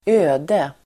Uttal: [²'ö:de]